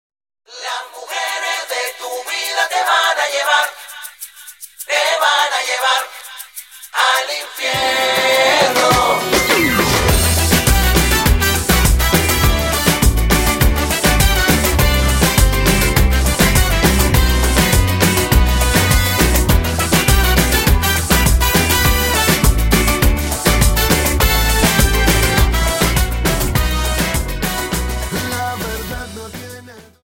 Samba 51 Song